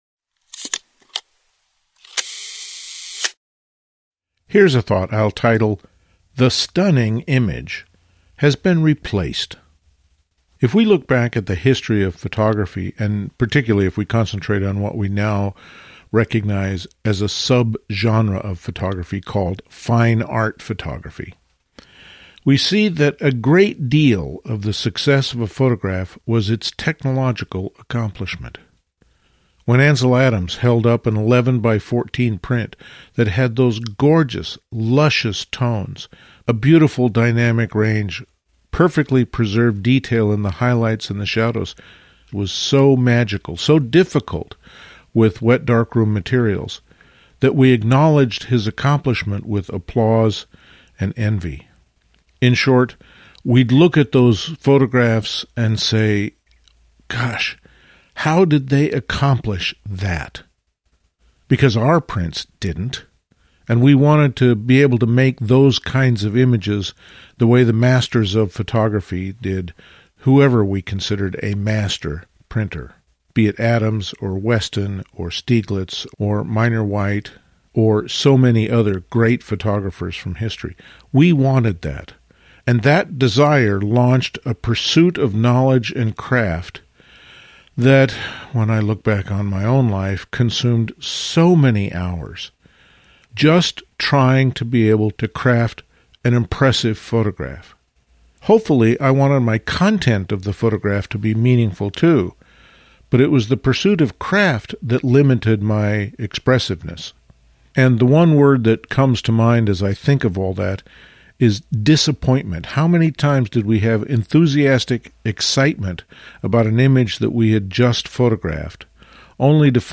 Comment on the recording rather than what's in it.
Included in this RSS Feed are the LensWork Podcasts — posted weekly, typically 10-20 minutes exploring a topic a bit more deeply — and our almost daily Here's a thought… audios (extracted from the videos.)